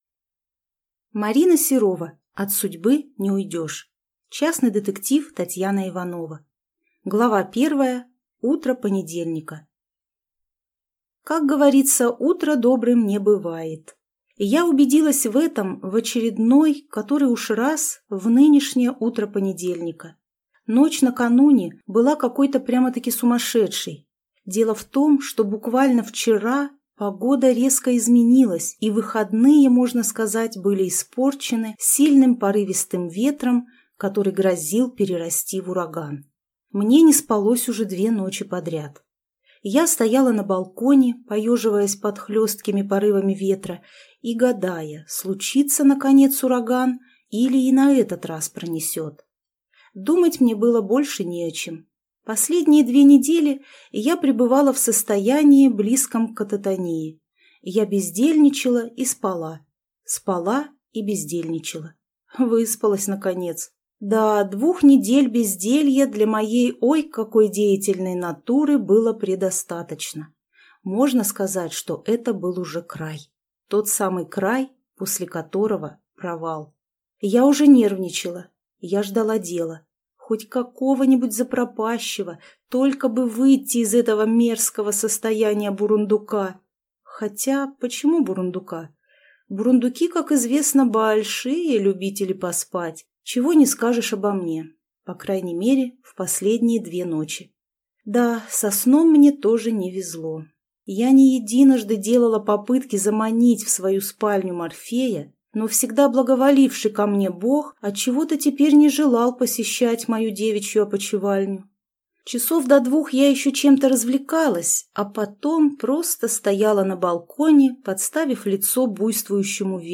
Аудиокнига От судьбы не уйдешь | Библиотека аудиокниг